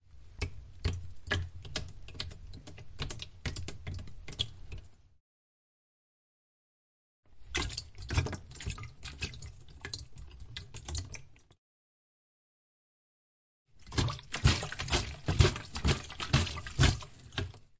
溅射
我在池塘边设置了录音机，当时天刚开始黑，然后在路上走了走。15分钟后我回来，决定捡起一块石头扔进去。这是它溅起的水花。
Tag: 池塘 岩石 飞溅